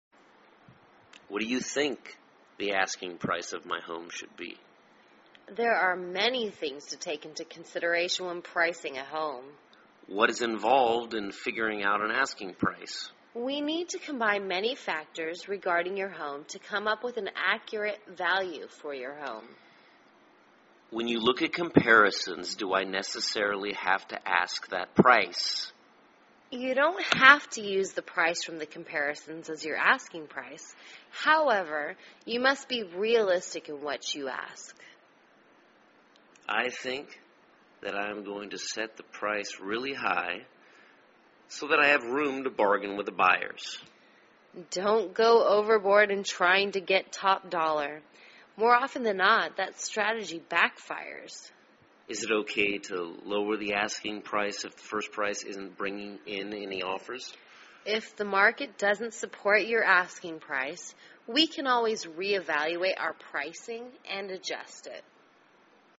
卖房英语对话-Setting an Asking Price(3) 听力文件下载—在线英语听力室